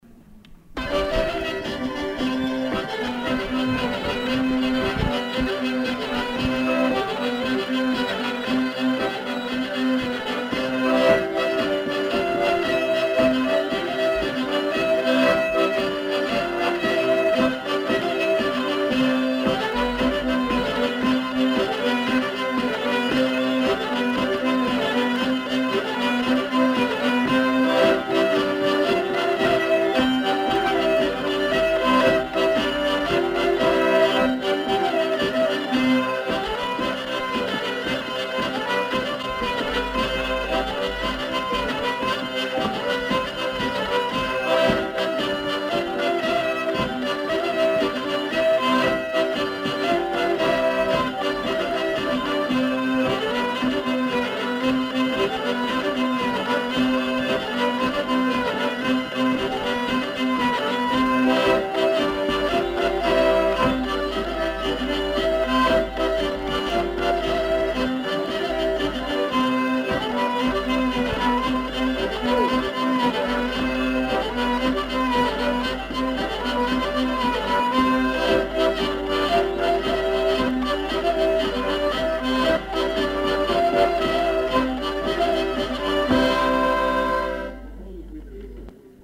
Aire culturelle : Savès
Genre : morceau instrumental
Instrument de musique : accordéon diatonique ; violon ; vielle à roue
Danse : rondeau